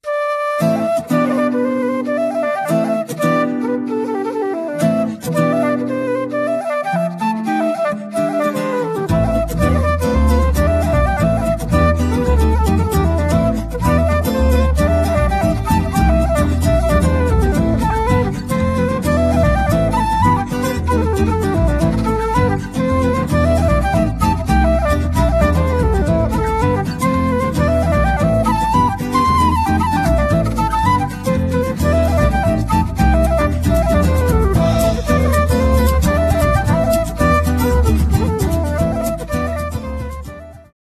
kontrabas / double bass